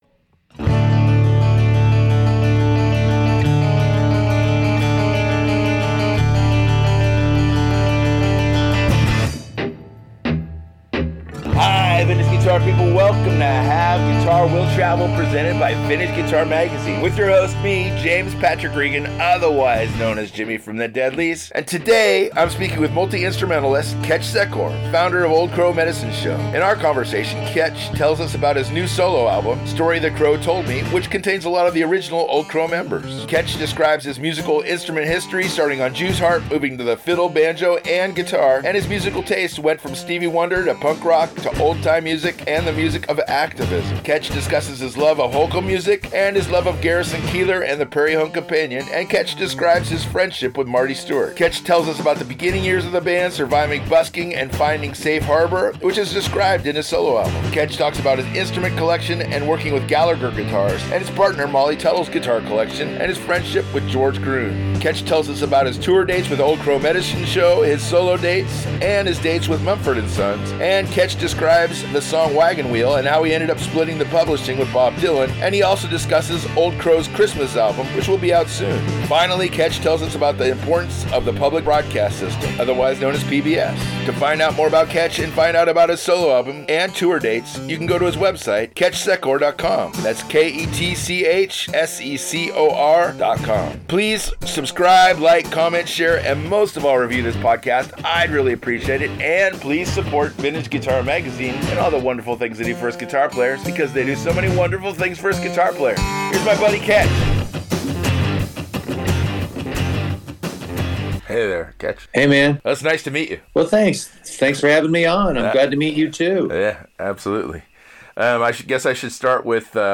In their conversation Ketch tells us about his new solo album “Story the Crow Told Me” which contains a lot of the original Old Crow members. Ketch describes his musical instrument history starting on jew’s harp, moving to the fiddle, banjo and guitar… and his musical tastes went from Stevie Wonder to punk rock to old time music and the music of activism.